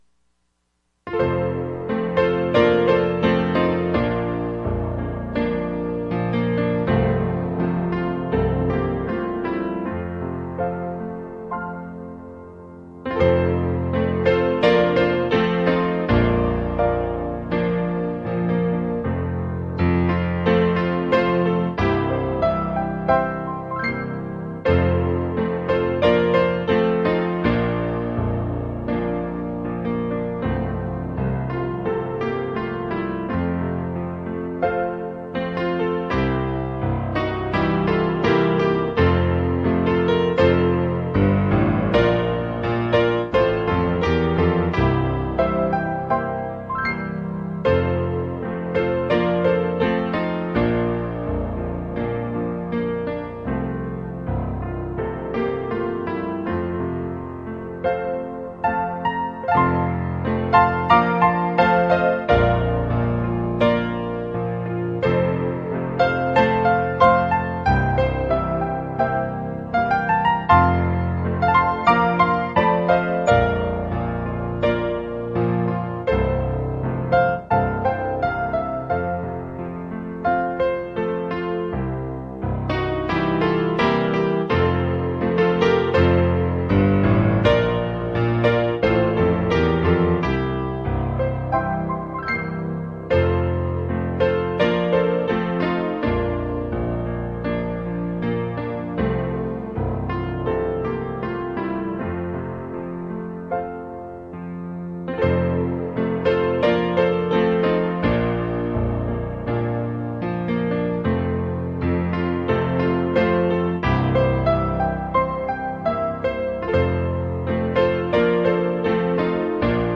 Bible Text: Revelation 7:1-8 | Preacher